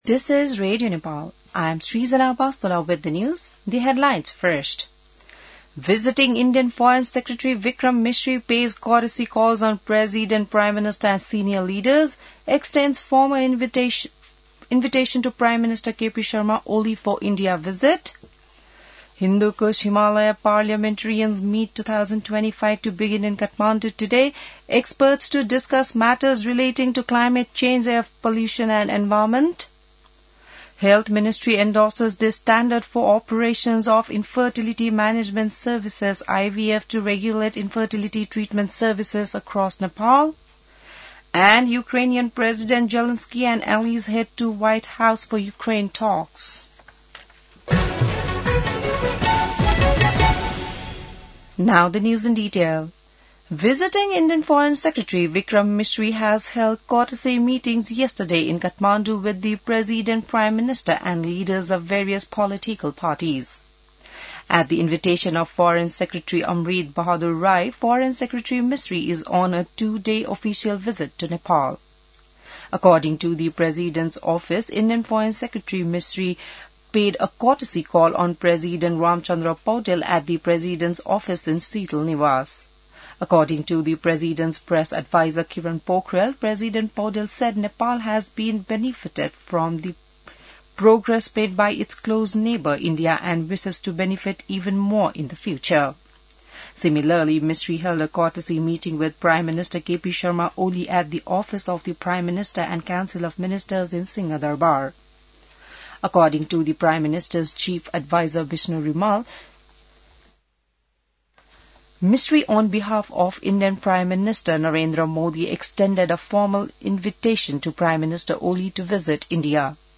An online outlet of Nepal's national radio broadcaster
बिहान ८ बजेको अङ्ग्रेजी समाचार : २ भदौ , २०८२